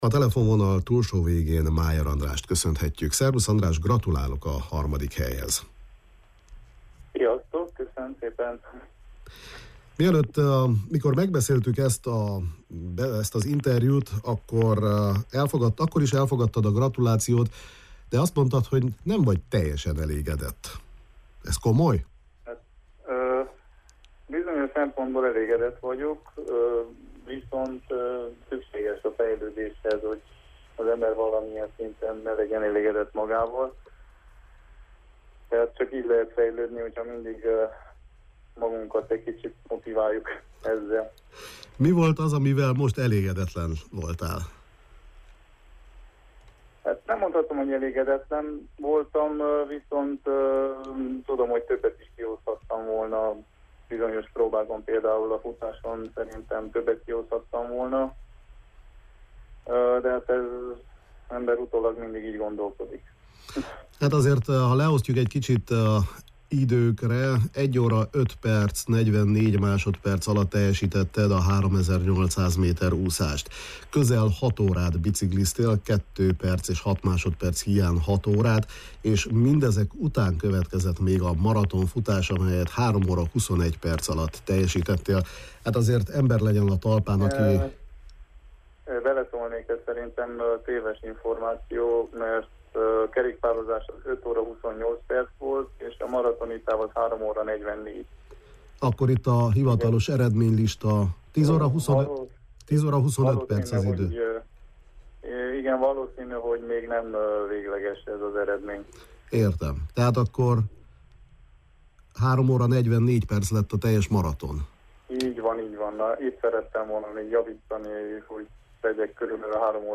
a KISPAD sportműsorunkban hívtuk fel telefonon: